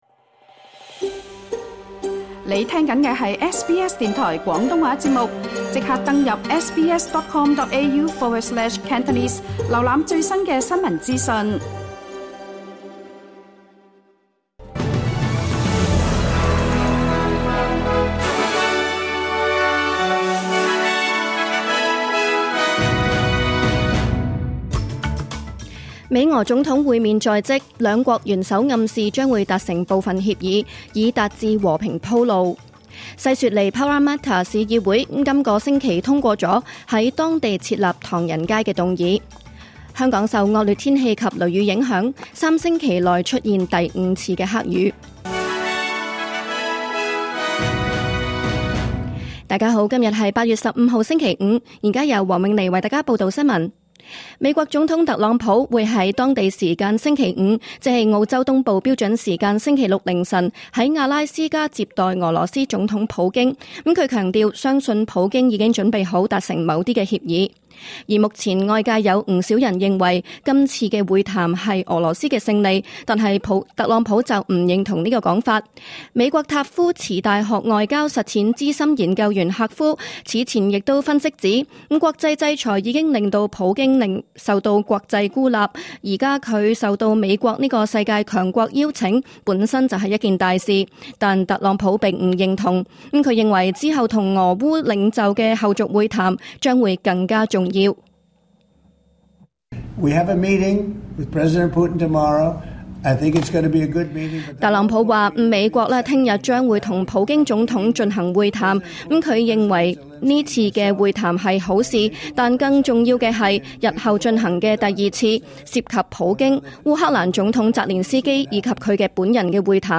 2025年8月15日SBS廣東話節目九點半新聞報道。